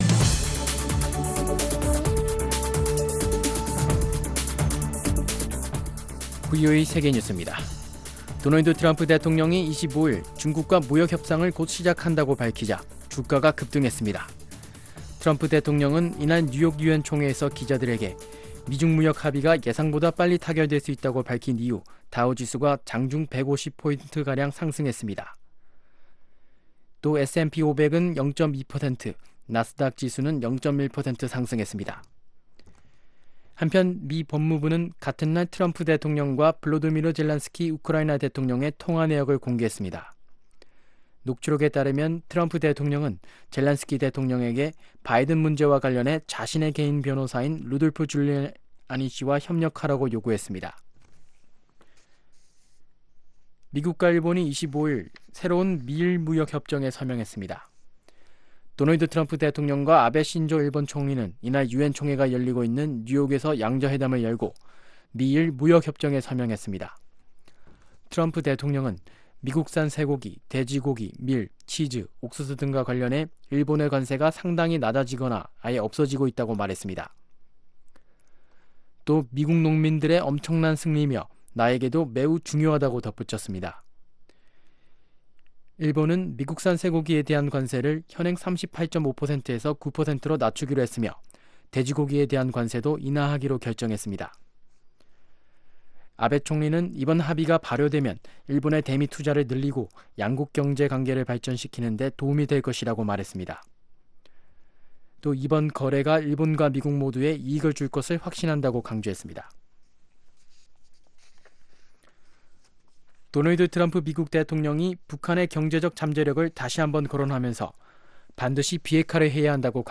VOA 한국어 아침 뉴스 프로그램 '워싱턴 뉴스 광장' 2019년 9월 26일 방송입니다. 도널드 트럼프 미국 대통령이 유엔총회 연설에서 북한의 무한한 잠재력을 실현하기 위해서는 핵을 포기해야만 한다면서 북한의 비핵화를 촉구했습니다. 서아프리카 세네갈에서 이뤄지고 있는 인권이 실종된 북한 노동자들의 노동현장, 북한의 제재 위반실태를 단독 취재했습니다.